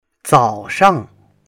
zao3shang.mp3